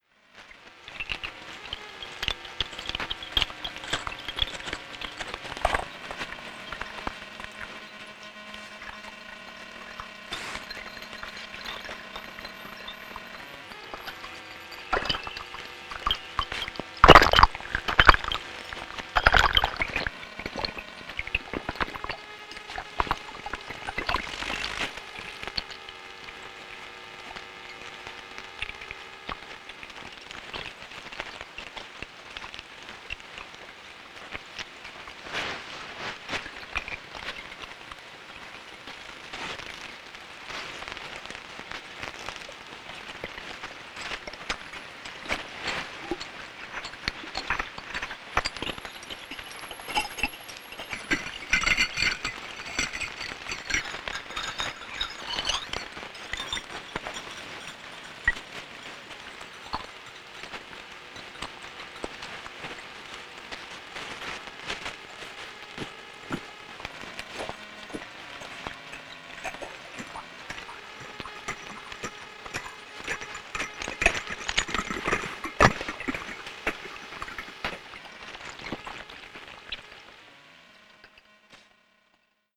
All ultrasonic sounds were captured and real time converted by bat detector. All tracks are unprocessed except for a little amplification.
Dogenzaka is a crowded street with lots of shops and hotels in Shibuya. Noticeable ultrasonic sounds are jangling sounds of accessories and keys as people walk down the street.
Recorded on June 2010, Tokyo, Japan.
Field Recording Series by Gruenrekorder